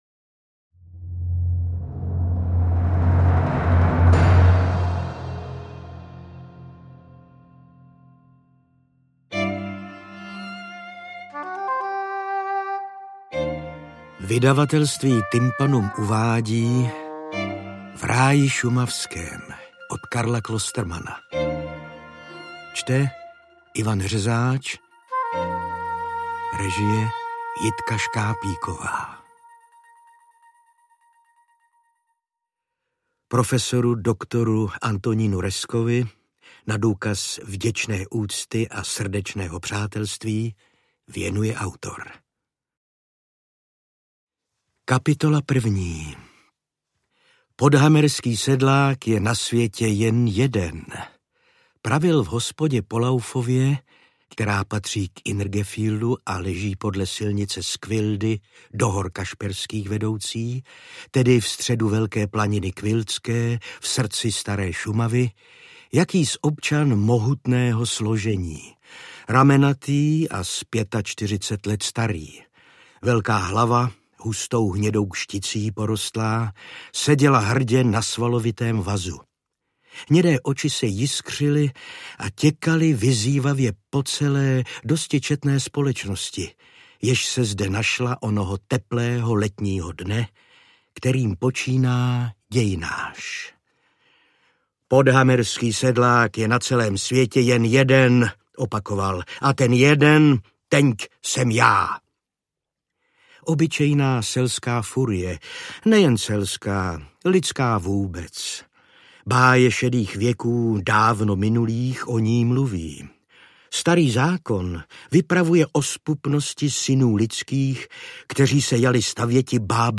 Interpret:  Ivan Řezáč
AudioKniha ke stažení, 30 x mp3, délka 12 hod. 44 min., velikost 701,9 MB, česky